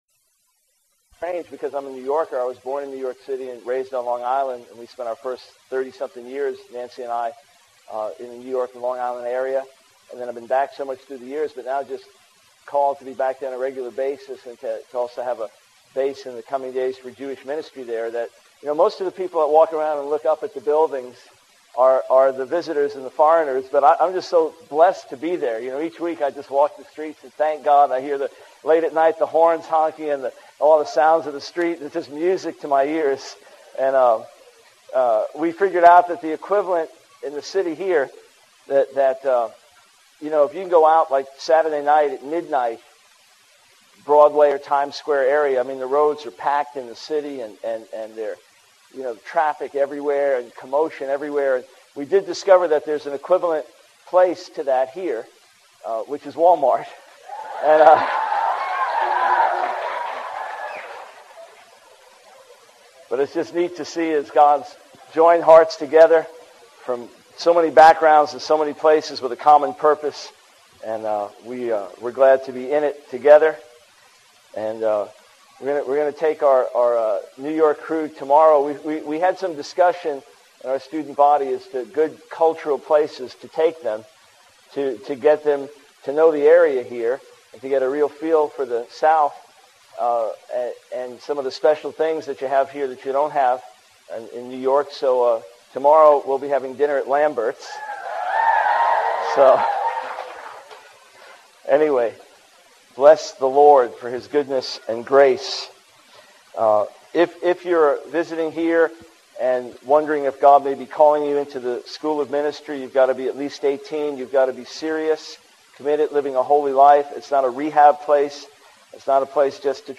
In this sermon, the speaker shares a powerful testimony of a Jewish man who survived the Holocaust and found faith in Jesus.